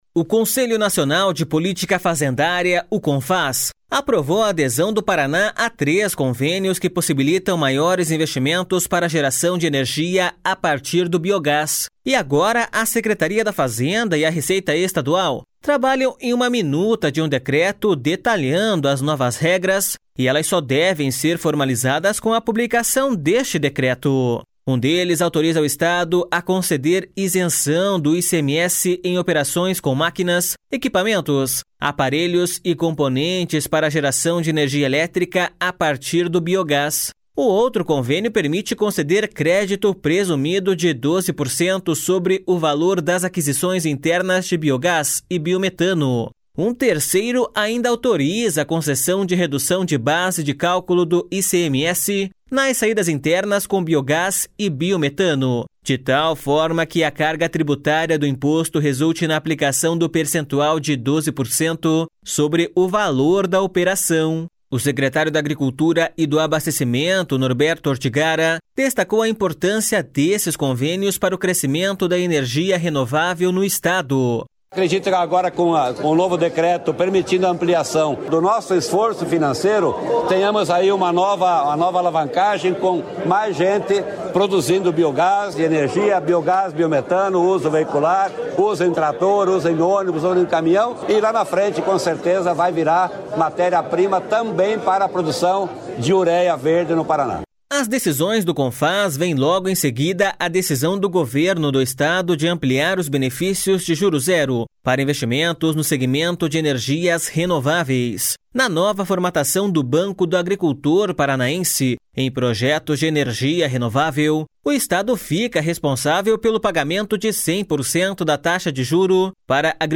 O secretário da Agricultura e do Abastecimento, Norberto Ortigara, destacou a importância desses convênios para o crescimento da energia renovável no Estado.// SONORA NORBERTO ORTIGARA.//